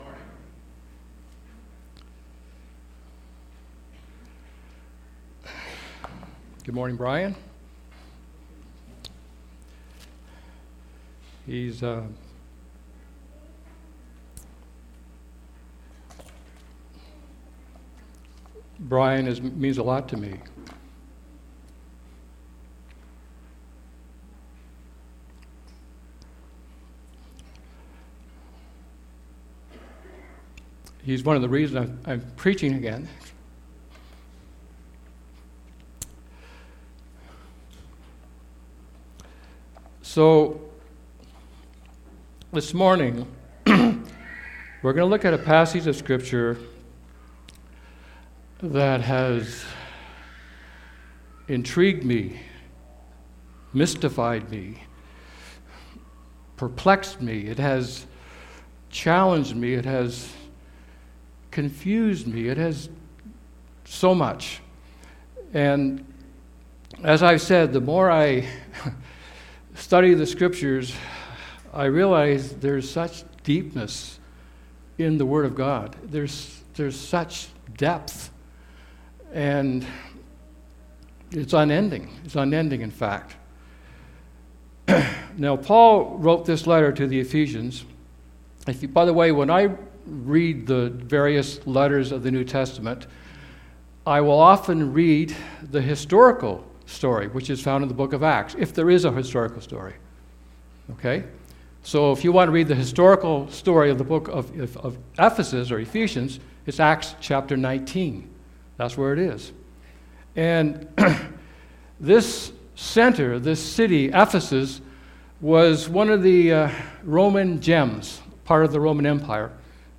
Sermon Audio and Video The Breadth